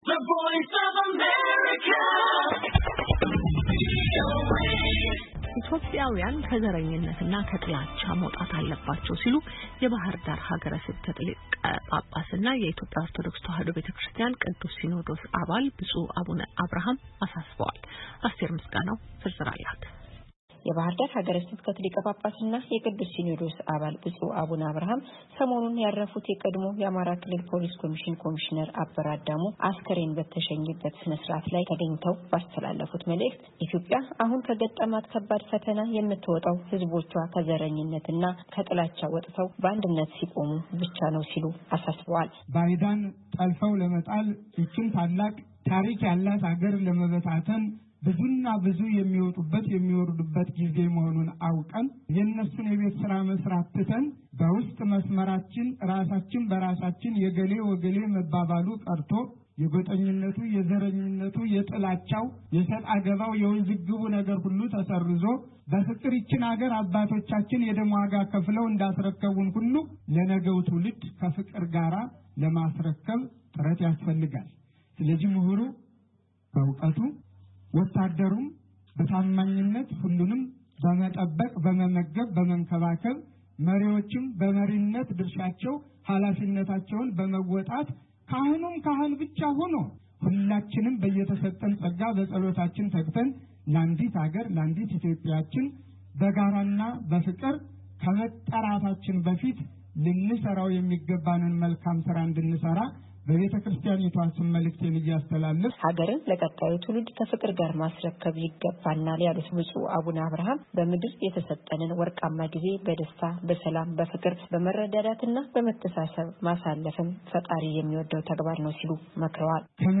የባሕር ዳር ሀገረ ስብከት ሊቀ ጳጳስና የቅዱስ ሲኖዶስ አባል ብፁዕ አቡነ አብርሐም ሰሞኑን ያረፉት የቀድሞው የአማራ ክልል ፖሊስ ኮሚሽነር አቶ አበረ አዳሙ አስከሬን በተሸኘበት ሥርዓተ ቀብር ላይ ተገኝተው ባስተላለፉት መልዕክት ኢትዮጵያ አሁን ከገጠማት ከባድ ፈተና የምትወጣው